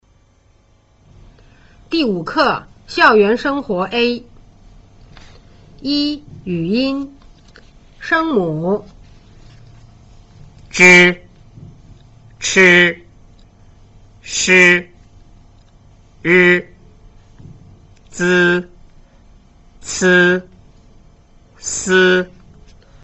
一、語音
聲母      :   zh   ch   sh   r   z   c   s